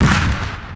Waka KICK Edited (41).wav